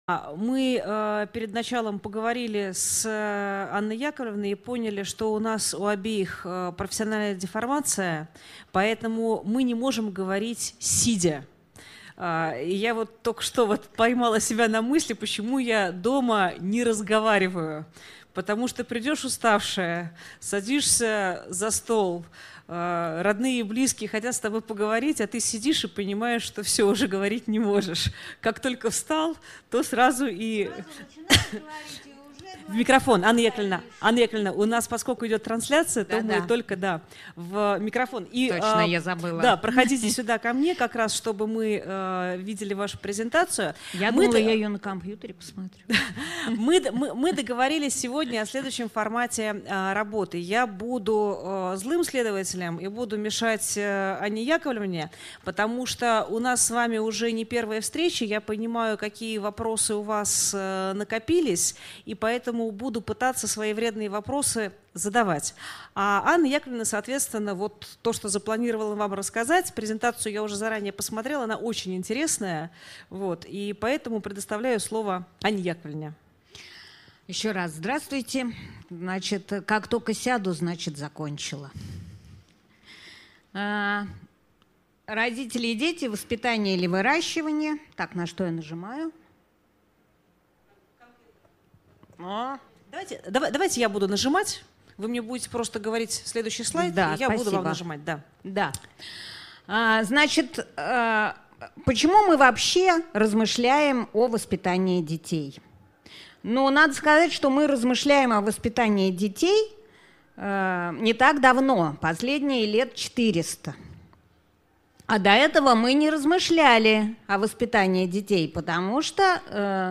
Аудиокнига Родители и дети: воспитание или выращивание | Библиотека аудиокниг